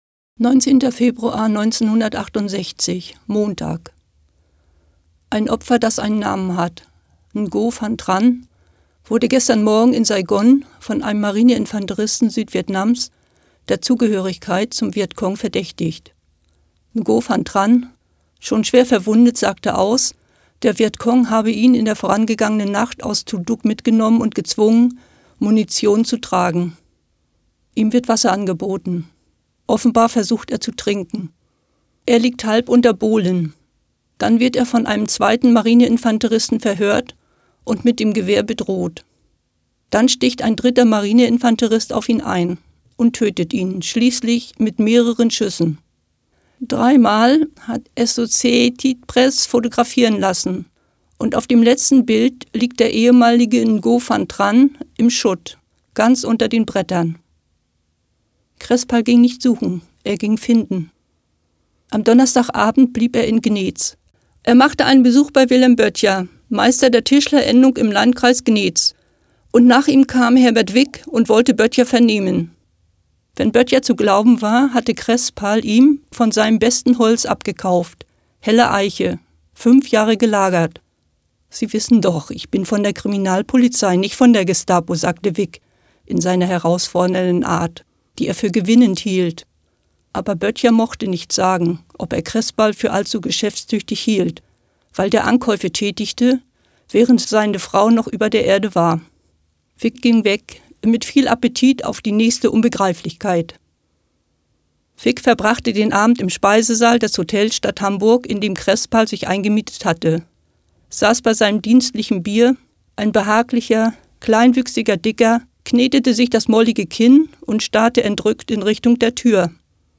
Eine Stadt liest Uwe Johnsons Jahrestage - 19.